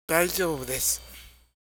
スピーキングバルブを使った肉声